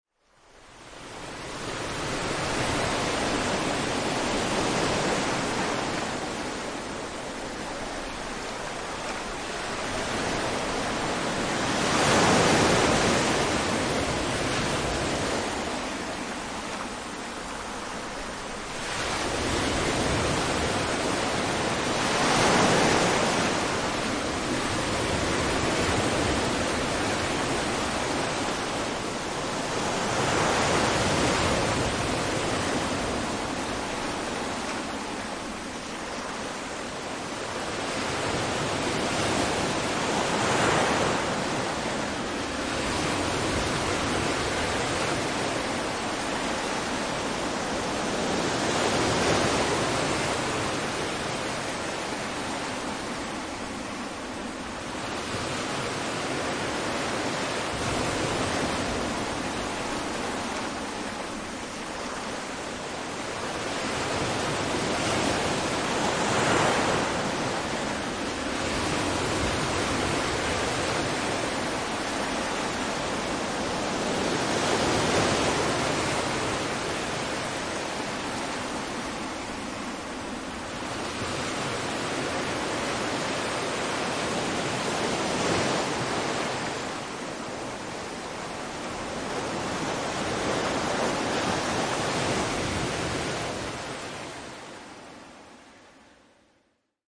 Seawater Surging.mp3